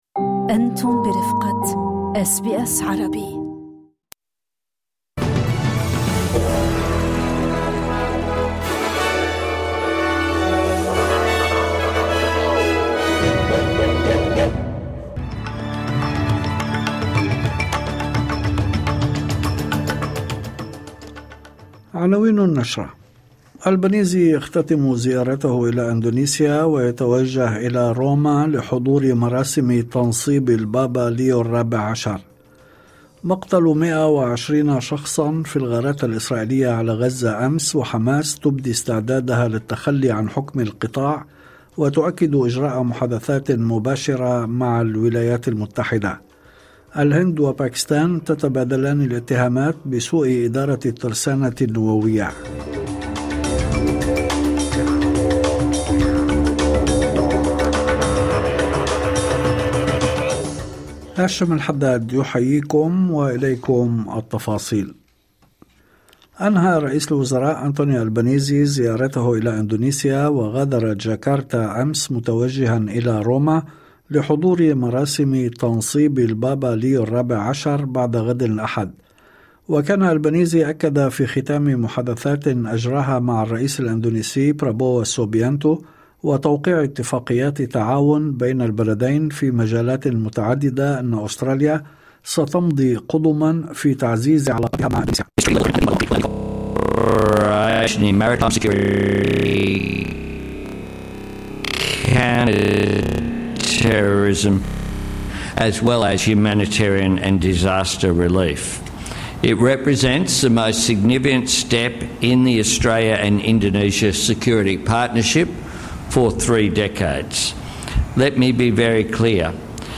نشرة أخبار المساء 16/5/2025